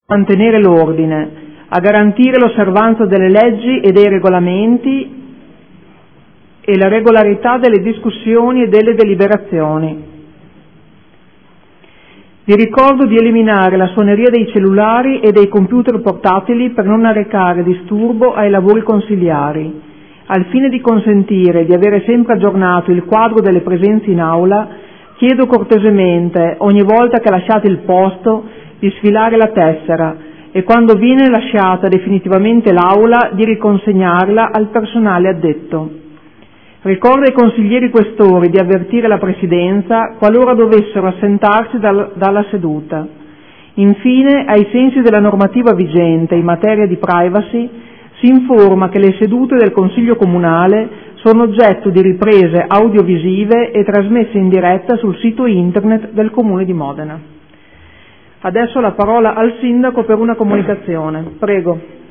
Seduta del 3/11/2014. Apre ai lavori del Consiglio.